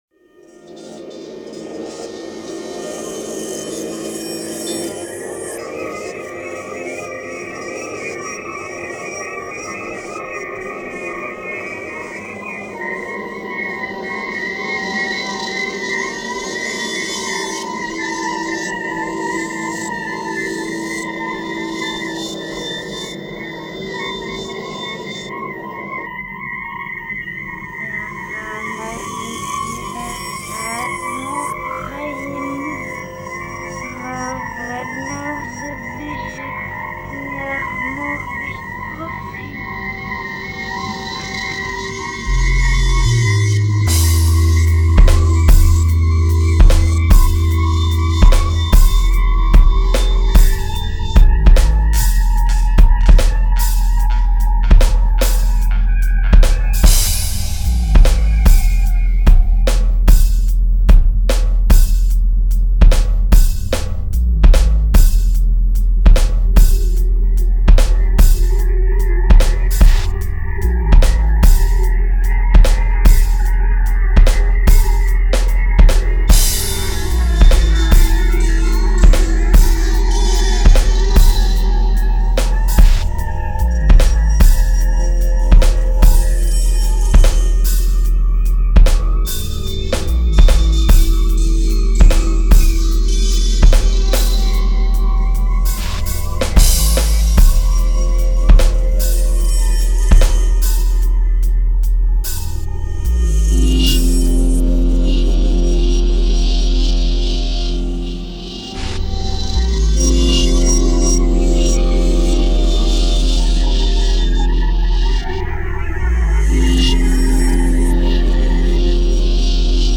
style: industrial abstract hip-dub